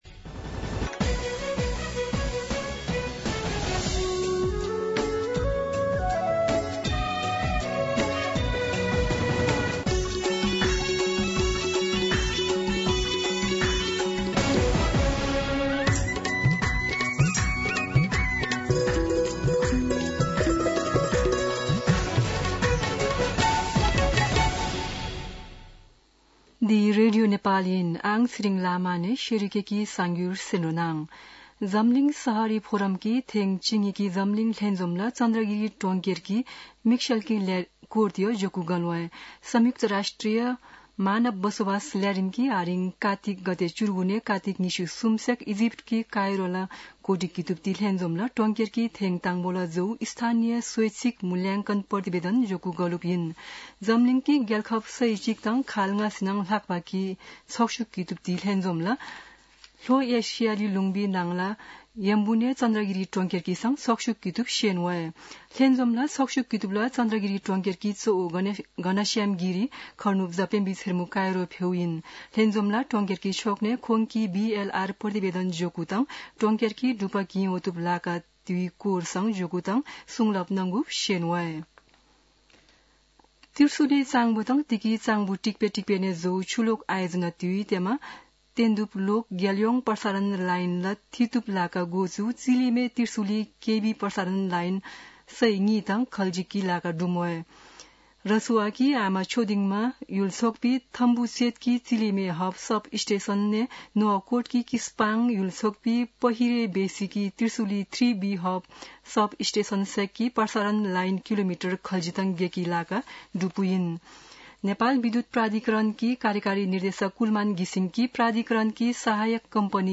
शेर्पा भाषाको समाचार : २० कार्तिक , २०८१
Sherpa-News-19.mp3